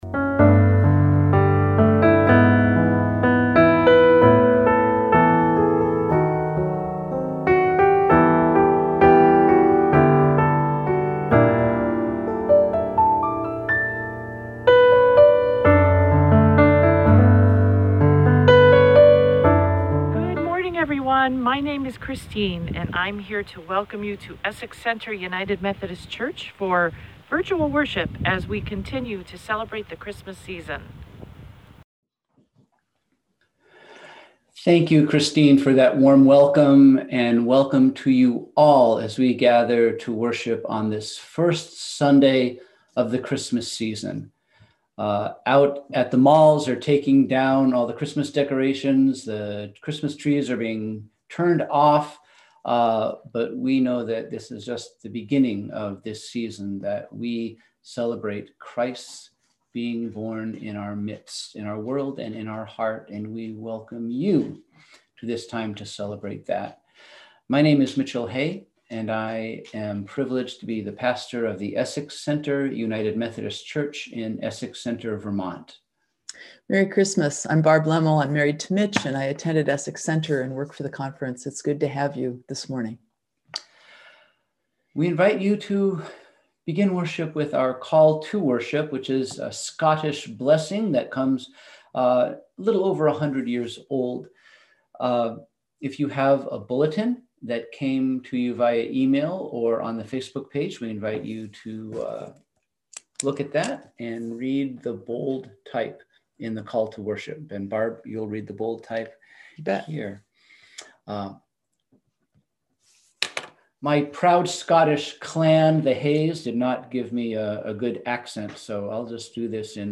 We held virtual worship on Sunday, December 27, 2020 at 10:00am!